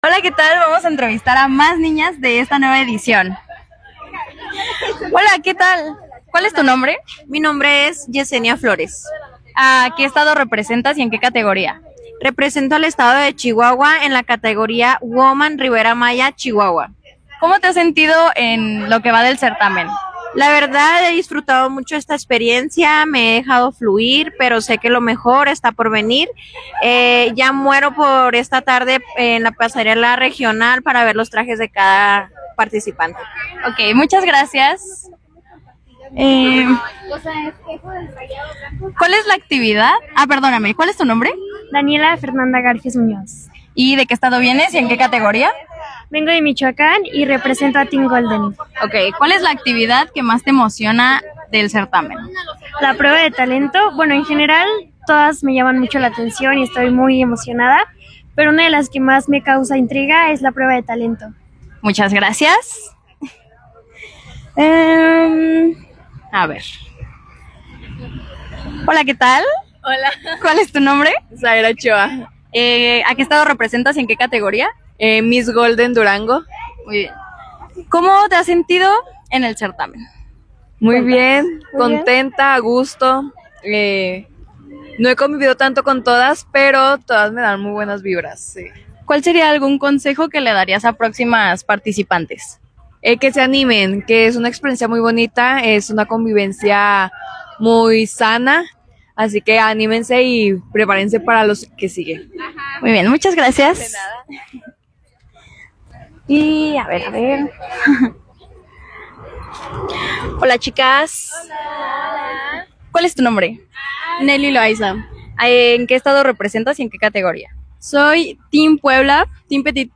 Continuación entrevistas miss petite